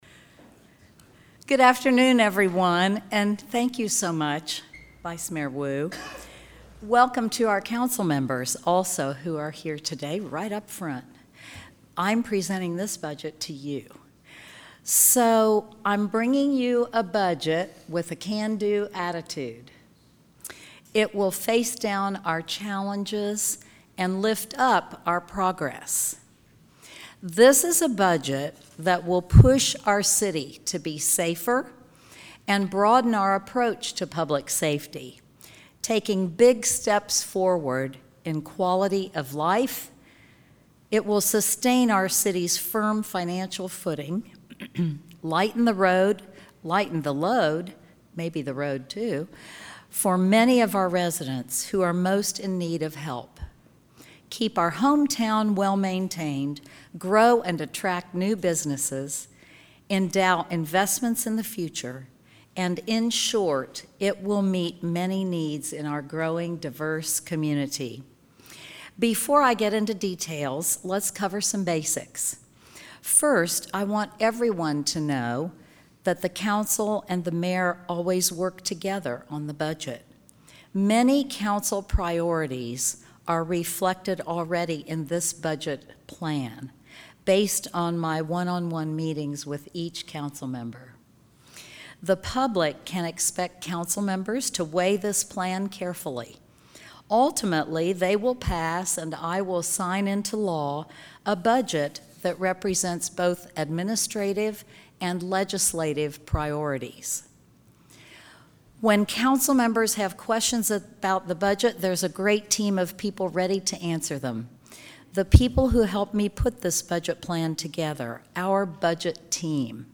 Here's Mayor Gorton's budget speech to Lexington Council members: ** WEKU is working hard to be a leading source for public service, fact-based journalism.